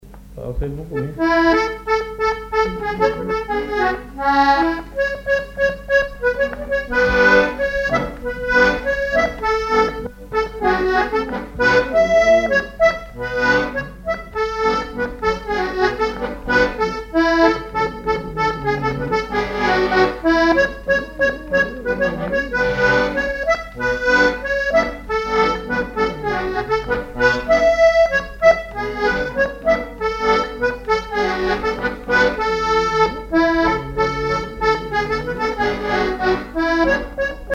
Chants brefs - A danser
danse : scottich sept pas
accordéon diatonique
Pièce musicale inédite